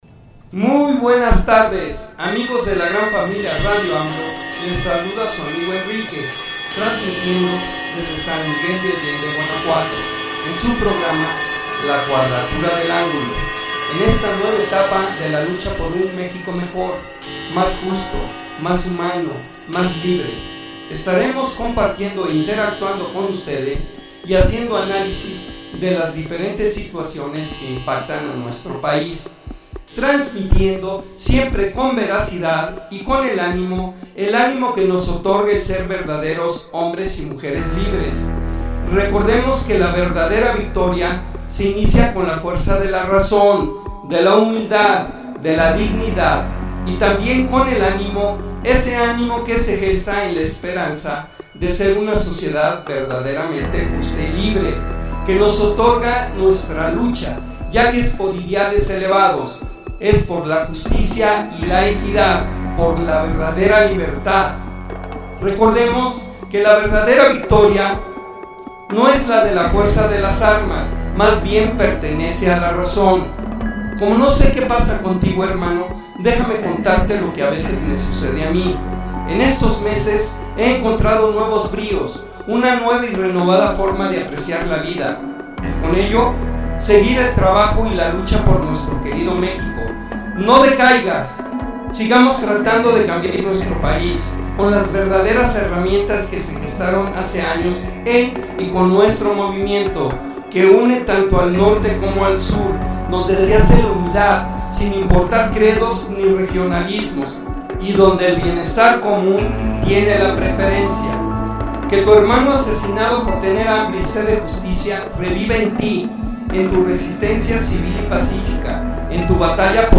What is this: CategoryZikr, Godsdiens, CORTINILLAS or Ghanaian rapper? CORTINILLAS